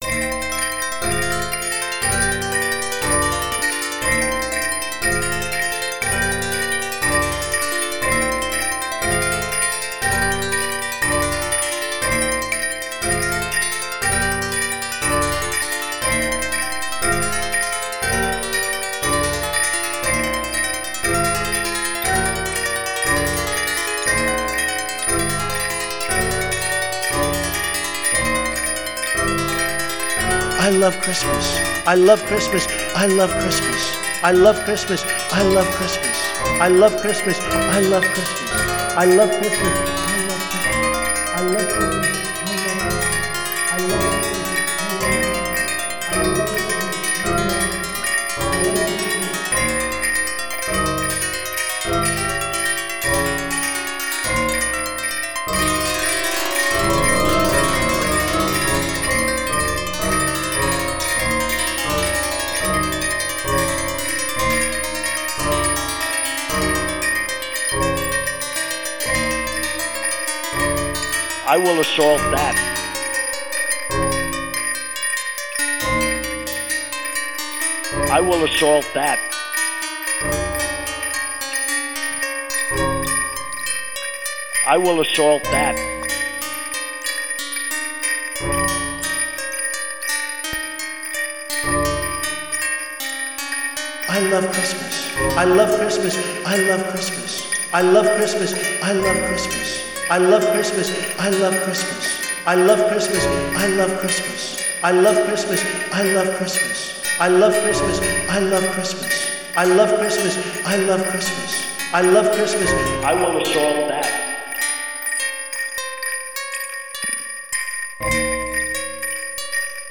It uses FM tubular bells, STKShaker Sleighbells and a Karplus Strong harp.
The voice is Donald Trump from two different occasions, talking about how, as president, he will pass a law mandating that all shops in the US wish patrons ‘Merry Christmas’, instead of ‘Happy Holidays.’
iLovechristmas-piece.mp3